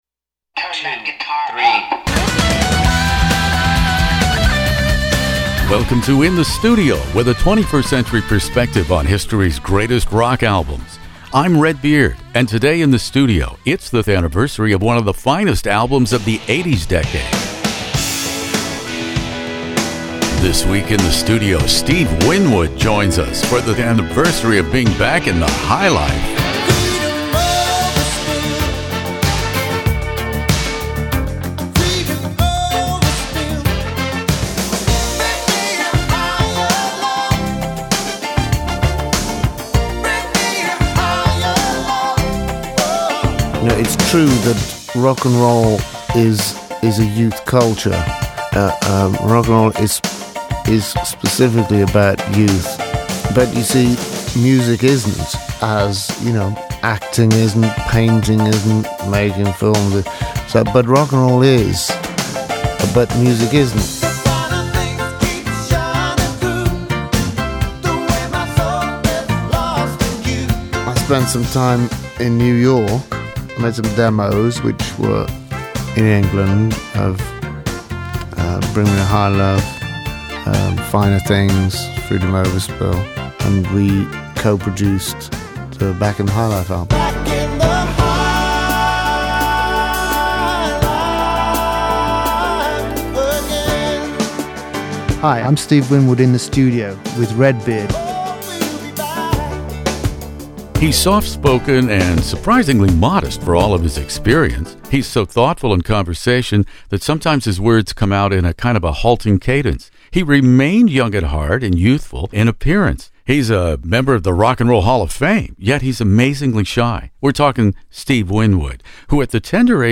Steve Winwood "Back in the High Life" interview In the Studio
Steve Winwood joins me here In the Studio .